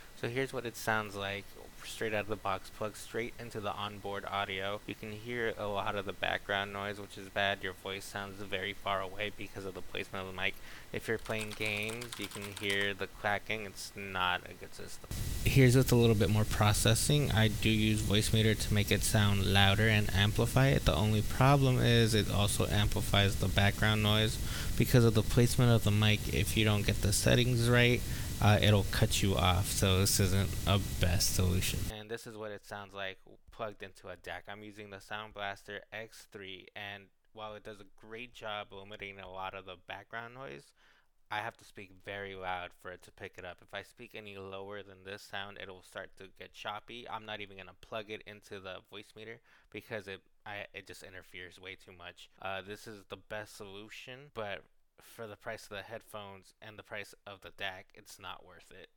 The mic does a terrible job of filtering any background noise. Whoever is on the receiving end of your voice chats will hear everything.
mictest.mp3